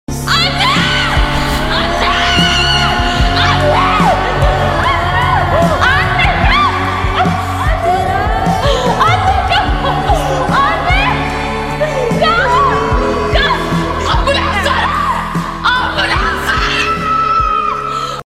Leyla crying for her mum sound effects free download